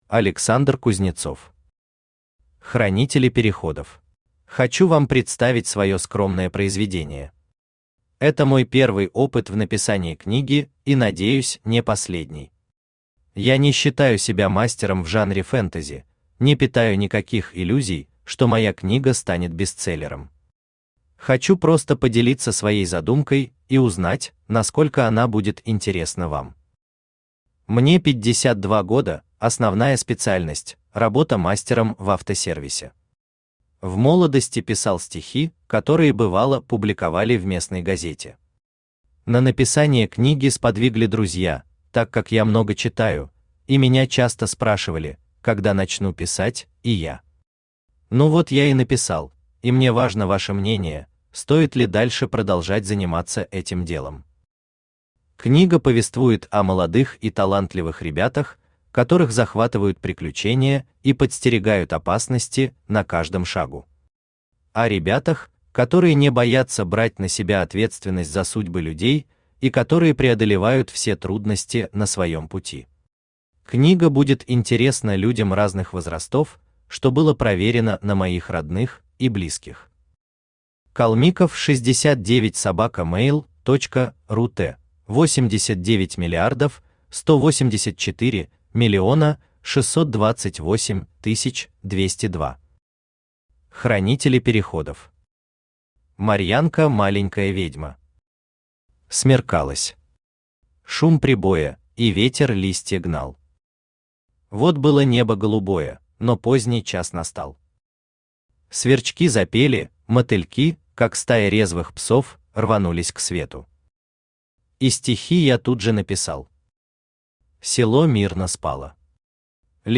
Аудиокнига Хранители переходов | Библиотека аудиокниг
Aудиокнига Хранители переходов Автор Александр Евгеньевич Кузнецов Читает аудиокнигу Авточтец ЛитРес.